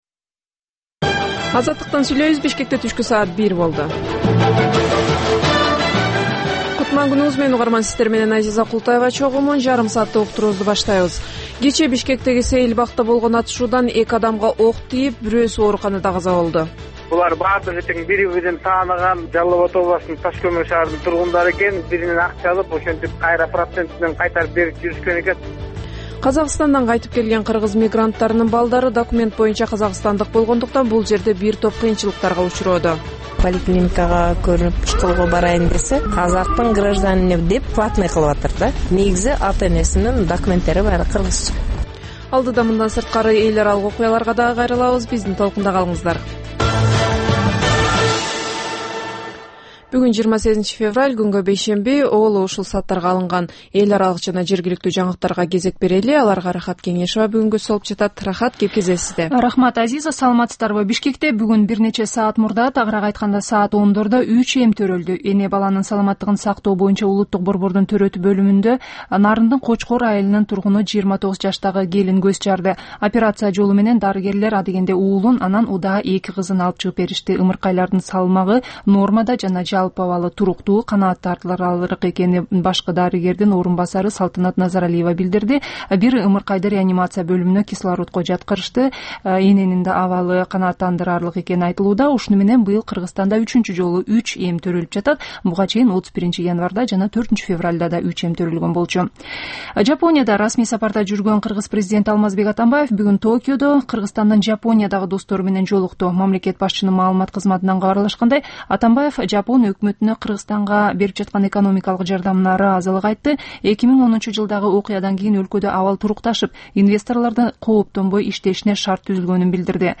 Бул түшкү үналгы берүү жергиликтүү жана эл аралык кабарлар, ар кыл орчун окуялар тууралуу репортаж, маек, талкуу, кыска баян жана башка оперативдүү берүүлөрдөн турат. "Азаттык үналгысынын" бул түш жаңы оогон учурдагы берүүсү Бишкек убакыты боюнча саат 13:00төн 13:30га чейин обого чыгарылат.